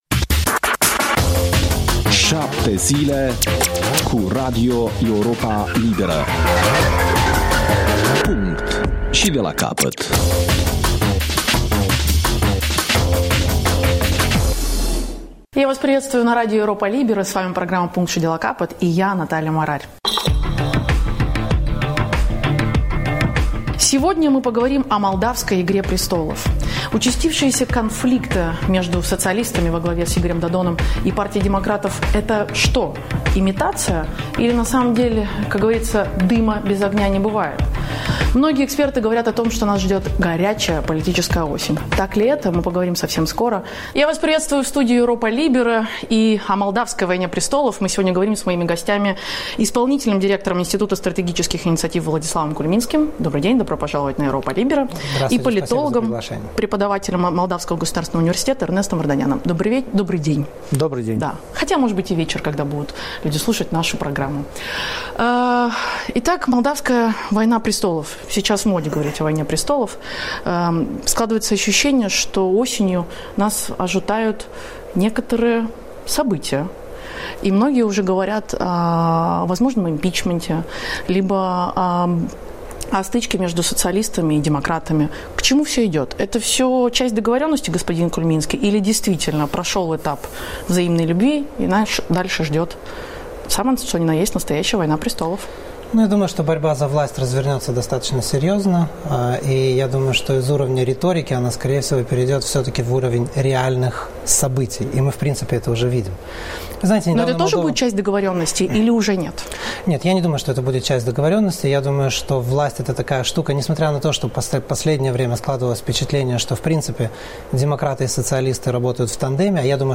Emisiune în limba rusă.